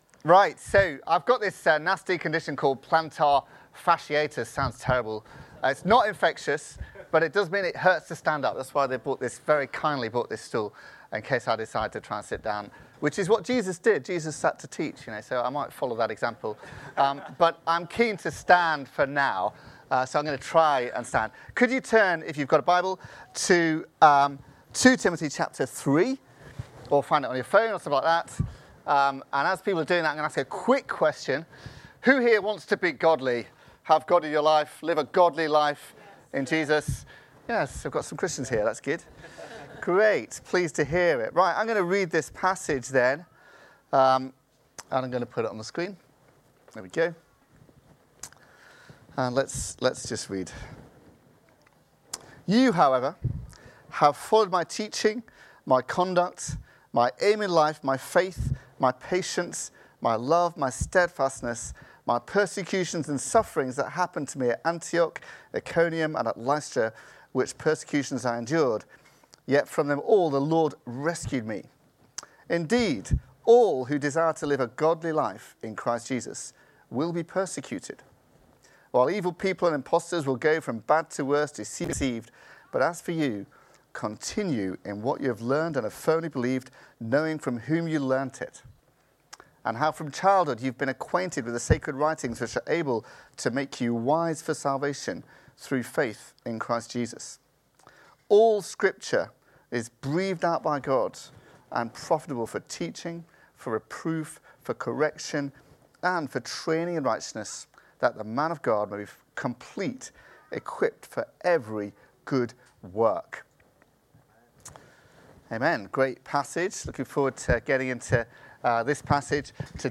Download The Scriptures | Sermons at Trinity Church